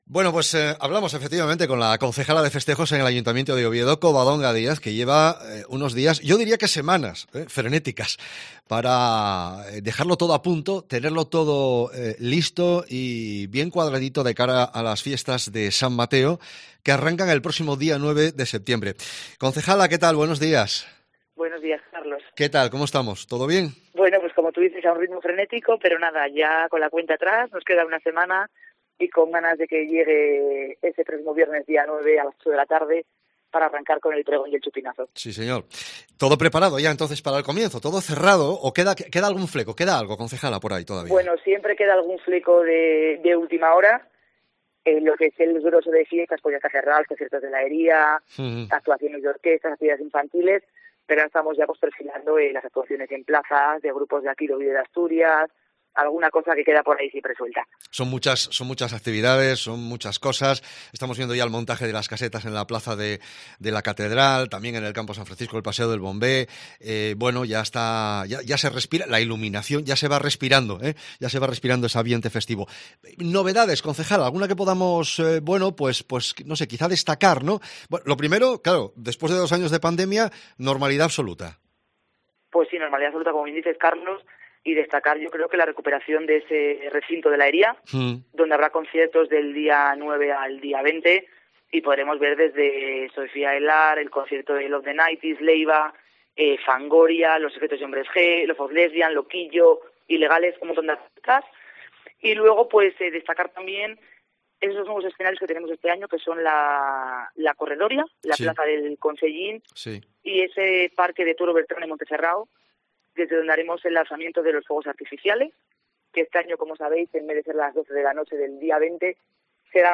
La concejala se ha pasado por los micrófonos de Cope Asturias, y aunque reconoce que queda "algún fleco de última hora" por cerrar, ya está todo prácticamente todo apunto para el comienzo de los festejos.